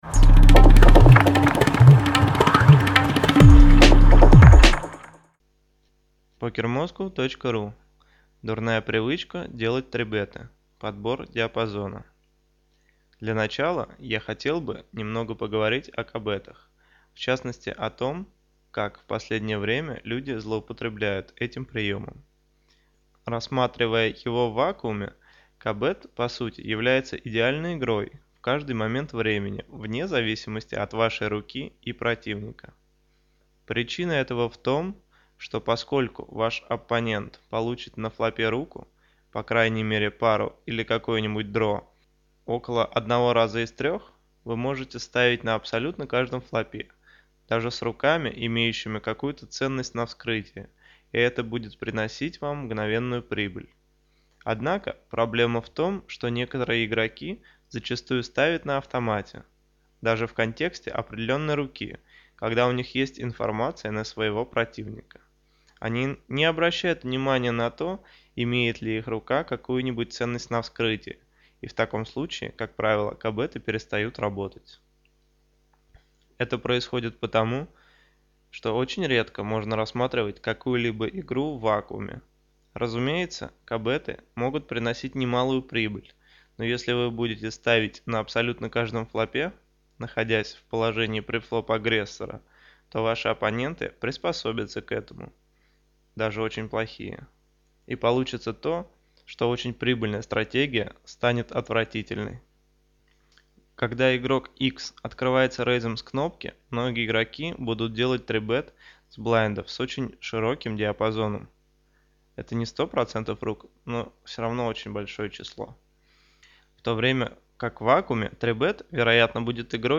Аудио-версия статьи «Дурная привычка делать 3-беты. Подбор диапазона»: